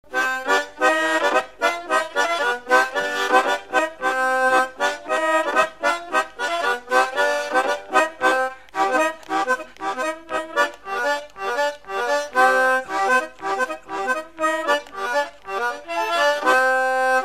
Saint-Michel-le-Cloucq
Chants brefs - A danser
Pièce musicale inédite